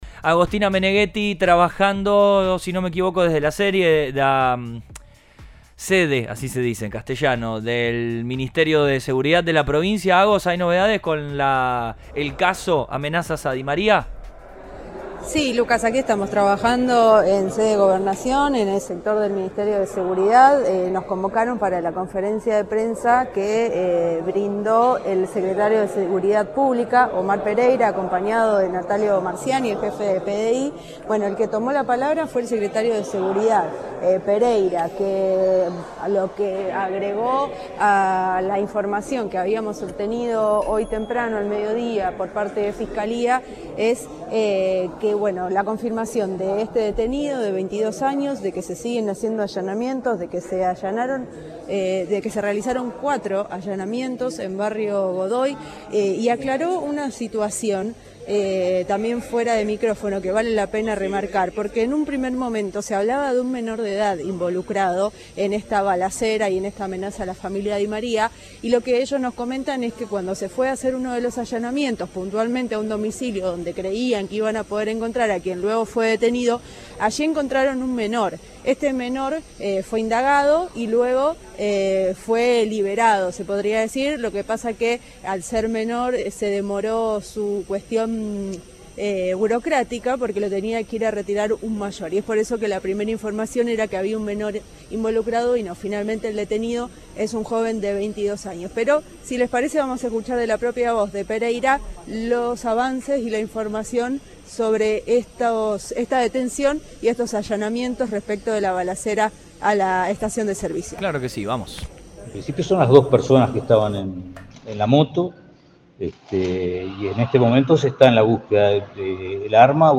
Tras la detención del primer sospechoso, funcionarios de Seguridad hablaron en conferencia de prensa sobre el avance de la causa por incidentes violentos en torno a la figura del futbolista.
En una conferencia de prensa conjunta entre el secretario de Seguridad Pública, Omar Pereira, y el jefe de la Policía de Investigaciones (PDI), Natalio Marciani, se brindaron detalles sobre la detención de un sospechoso vinculado al ataque a la estación de servicio Puma en la intersección de las calles Oroño y Lamadrid, en Rosario.